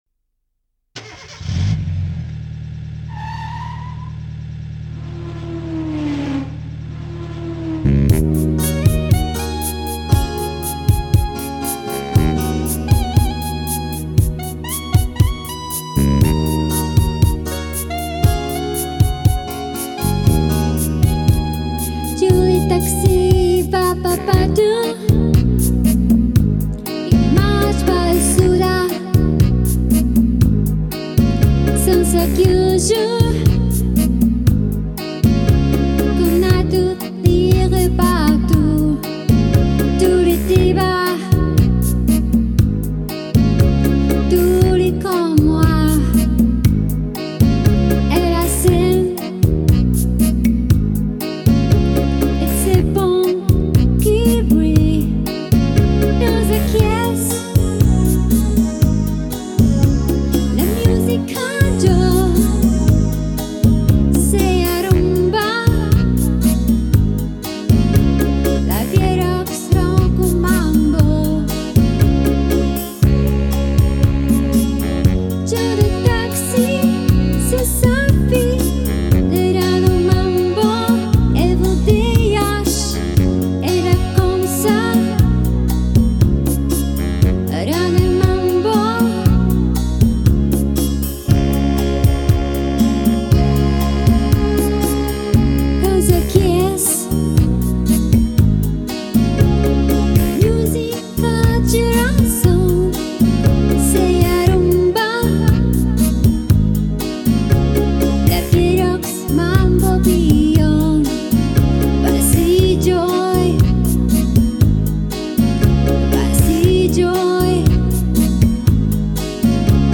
А ЭТО - моя знакомая ,по польскому форуму,поёт!